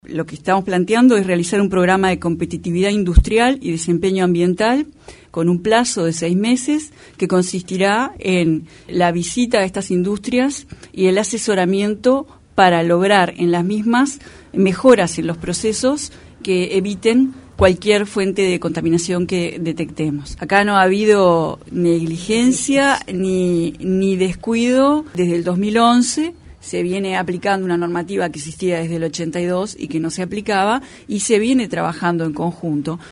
Según dijo este miércoles, en conferencia de prensa, se están aplicando medidas desde el año 2011 para mitigar el impacto de las empresas en el río del cual OSE extrae el agua para abastecer al área metropolitana.